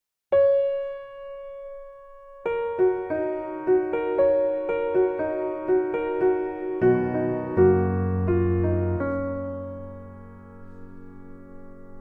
Happy Birthday Crowd
Applause Birthday Clapping Crowd Group Happy Happy-Birthday Outdoor sound effect free sound royalty free Sound Effects